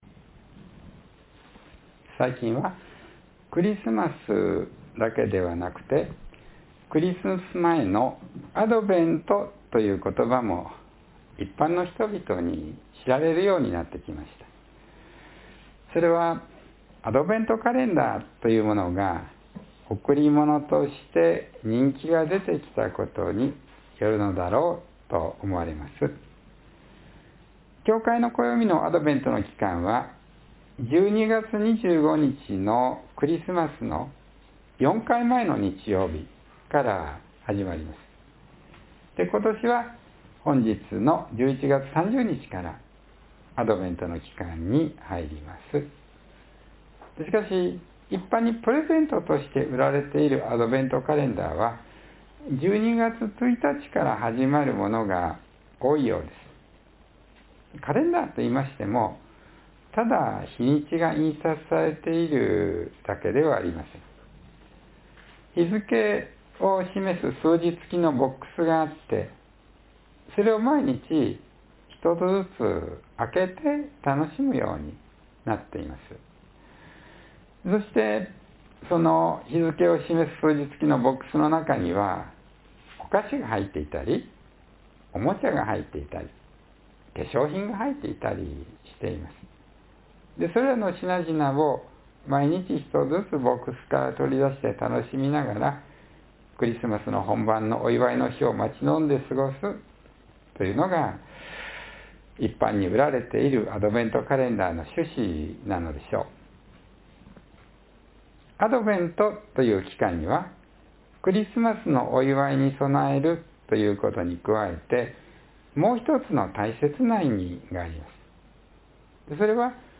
(11月30日の説教より)